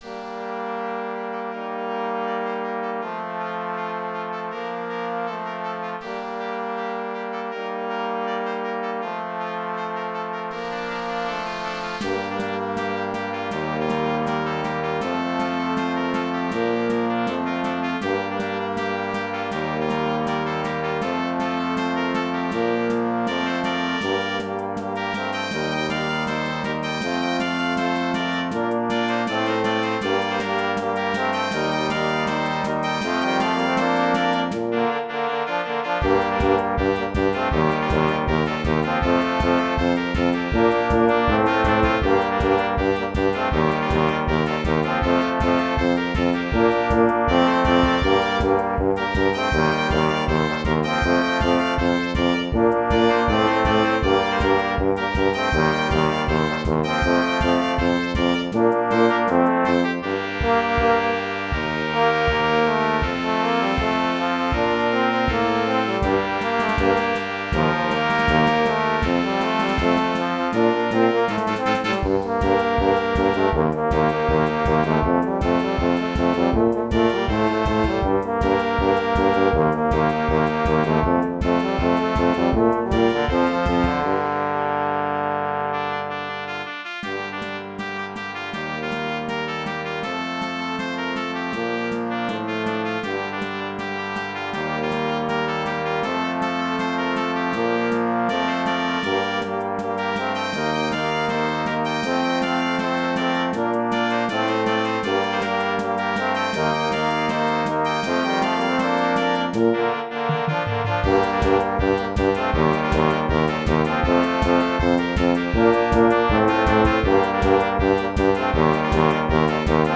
erstmals für Blasmusik spielbar.
Song – Kleine Besetzung-Fassung zum Anhören: